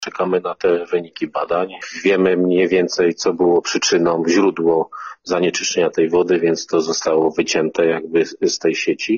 – Przypuszczamy, że problem był ze świeżo oddanym odcinkiem nitki wodociągu. Prawdopodobnie to spowodowało, że jakaś substancja weszła z tą wodą w reakcję i wpłynęła dalej do sieci – mówił nam Piotr Kucia, zastępca prezydenta Bielska-Białej.